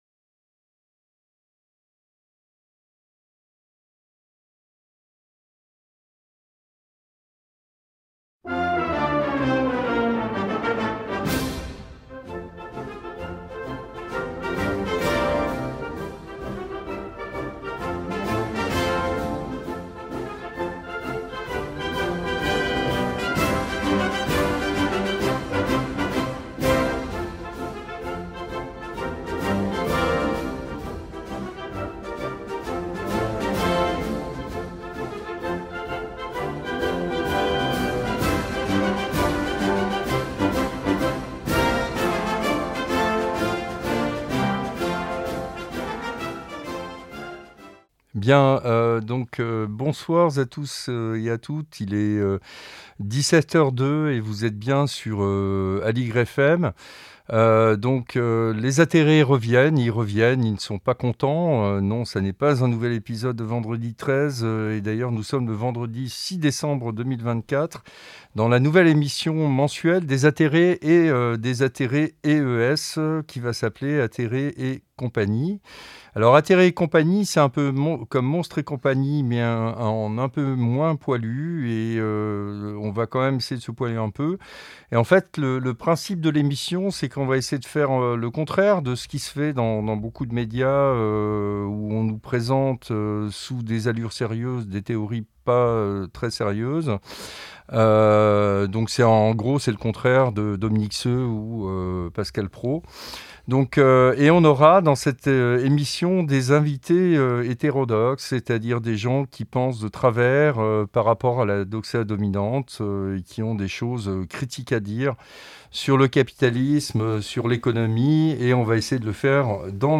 Mais on va quand même essayer de se poiler un peu. Émission au ton un peu décalé, mais sérieuse sur le fond donc garantie sans Dominique Seux ni Agnès verdier Molinier Avec des invités hétérodoxes - des gens qui pensent de travers par rapport à la doxa dominante.